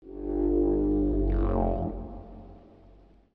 whale-sound